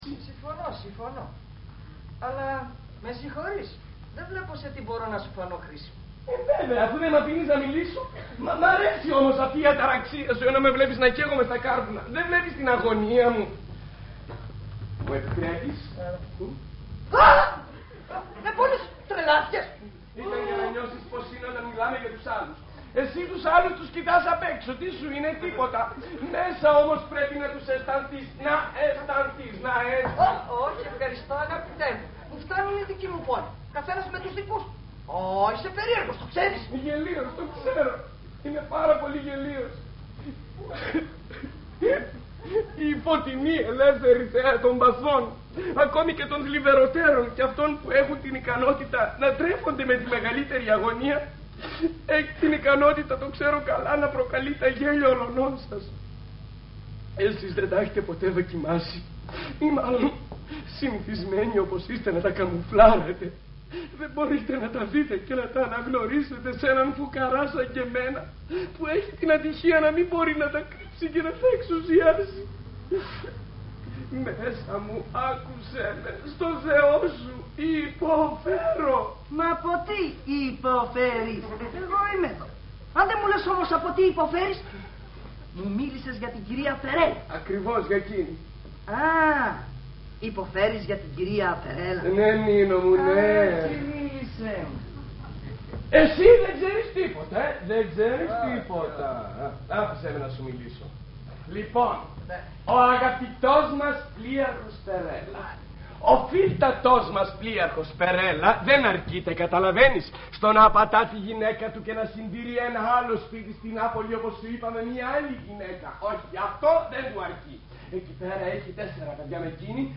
Ηχογράφηση Παράστασης
Αποσπάσματα από την παράσταση